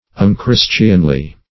Unchristianly \Un*chris"tian*ly\, a.
unchristianly.mp3